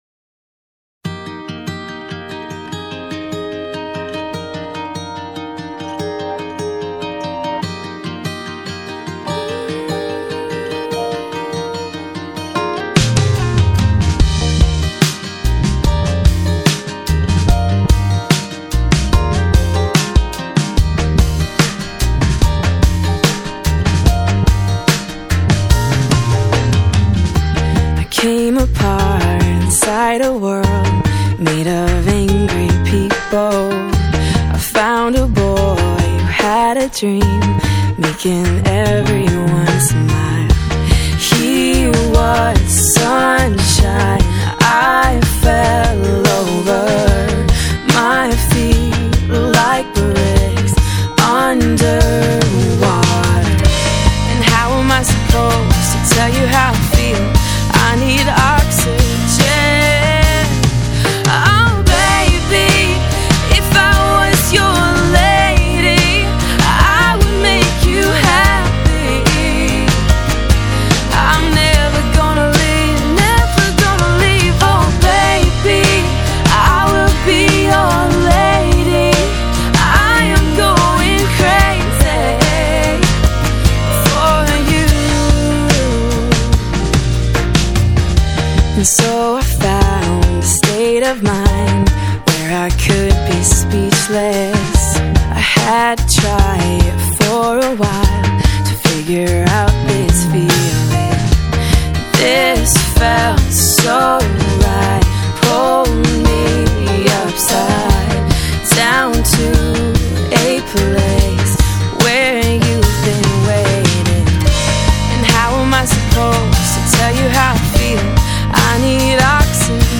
Pop, Acoustic Pop